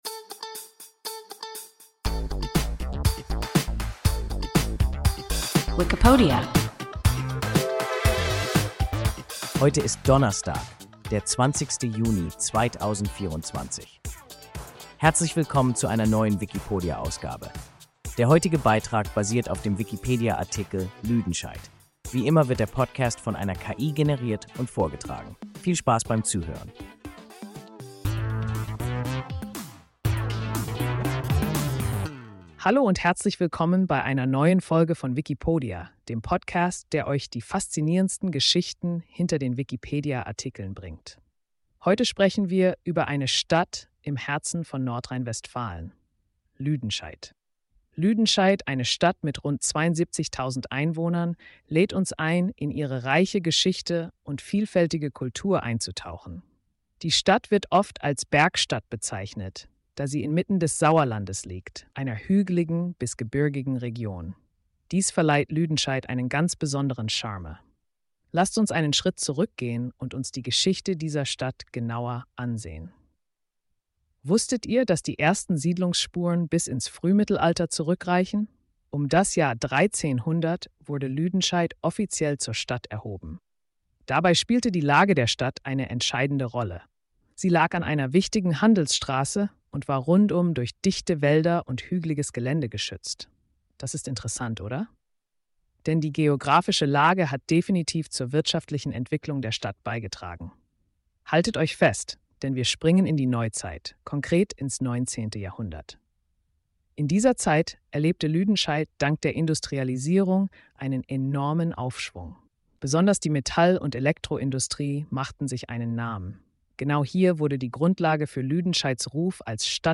Lüdenscheid – WIKIPODIA – ein KI Podcast